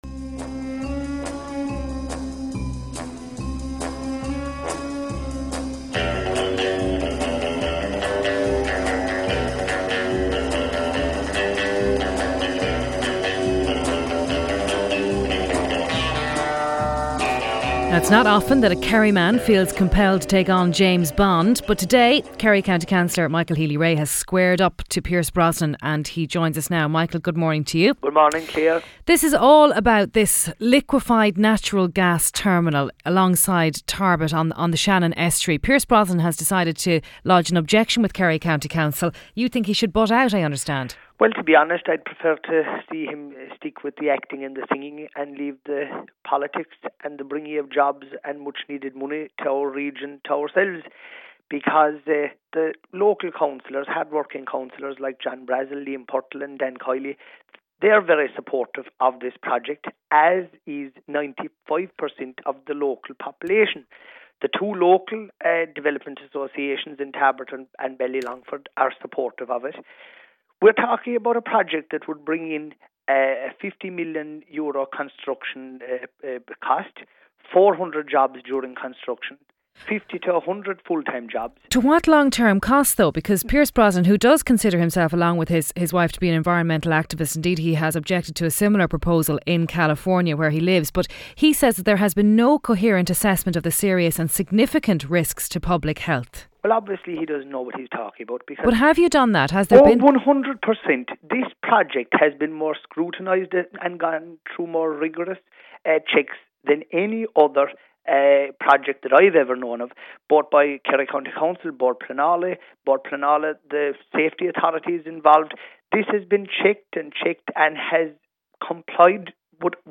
Interview by Claire Byrne of the national radio station “Newstalk 106-108 FM” with Michael Healy-Rae, a Kerry County Councillor from the Killorglin Electoral Area of South Kerry following Pierce Brosnan's submission to Kerry County Council on the proposed LNG regasification terminal at Tarbert in North Kerry.